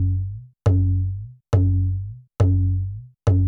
TABLA BASS-L.wav